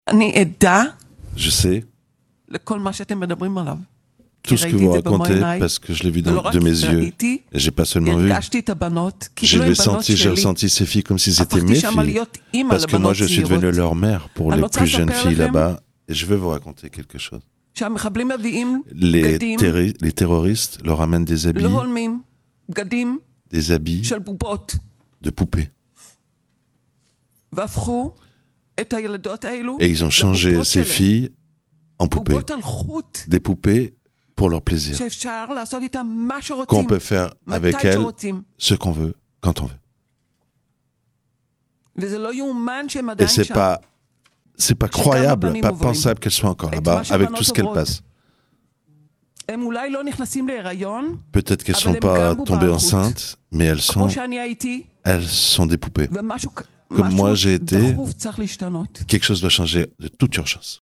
qui a été libérée de captivité du Hamas est venue témoigner devant une commission de la Knesset